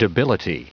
Prononciation du mot debility en anglais (fichier audio)
Prononciation du mot : debility